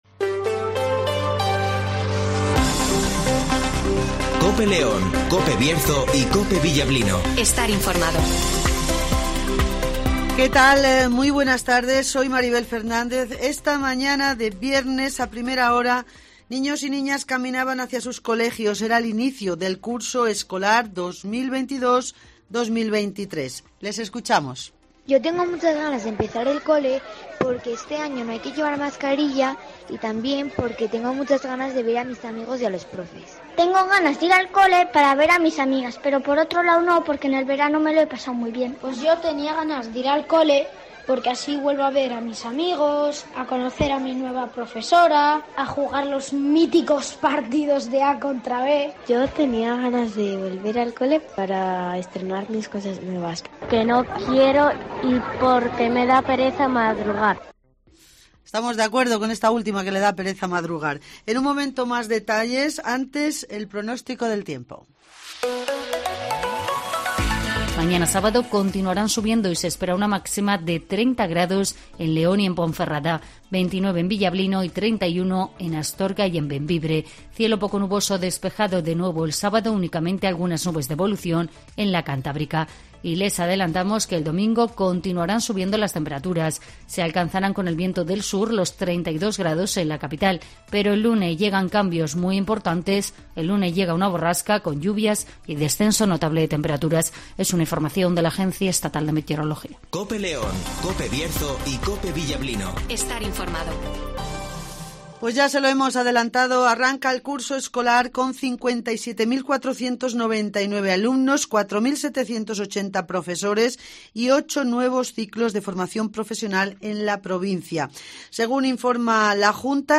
INFORMATIVOS
Repaso a la actualidad informativa de León capital, del Bierzo y del resto de la provincia. Escucha aquí las noticias con las voces de los protagonistas.